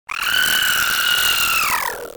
Alien goat screaming sound effect
Alien Creature Funny Screaming sound effect free sound royalty free Voices